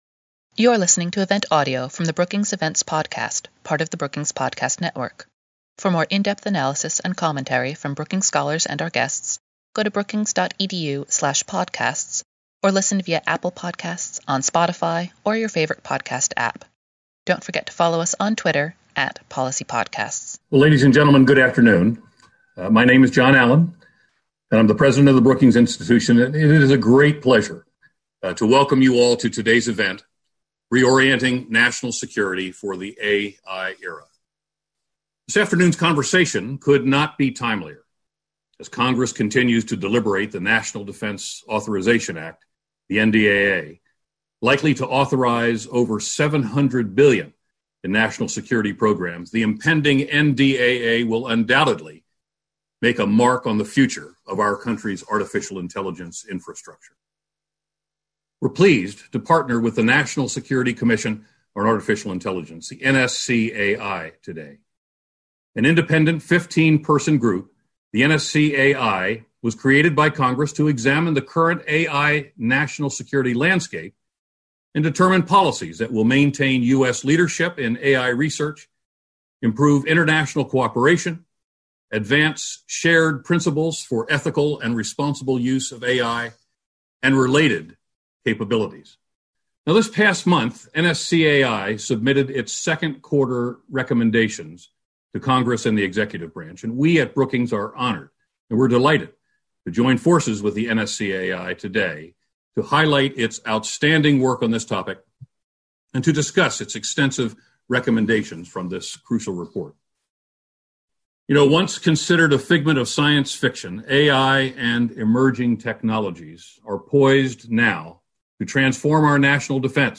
On July 29, Brookings hosted a conversation with NSCAI Chair Dr. Eric Schmidt and Vice Chair Mr. Robert Work on the current state of artificial intelligence in the national security environment, and the commission’s latest recommendations to spur progress on the responsible development and deployment of AI technologies. Brookings President John Allen moderated the discussion.